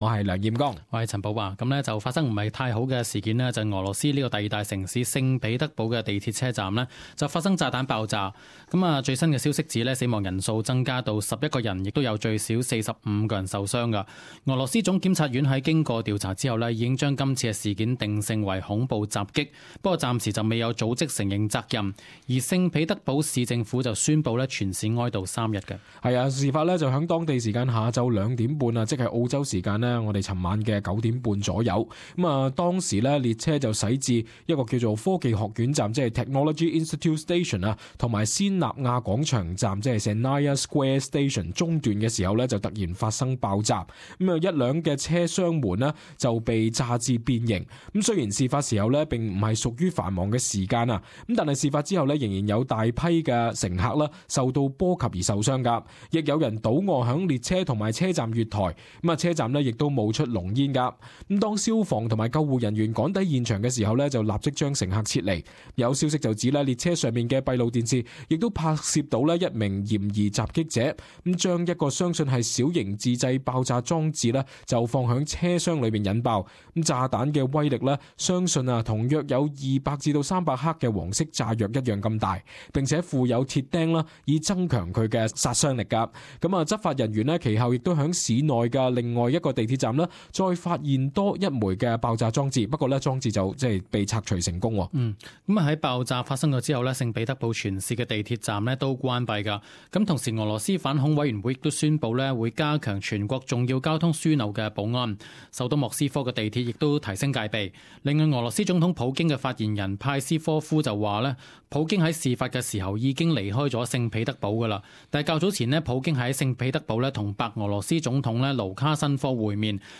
【時事報導】聖彼得堡地鐵恐襲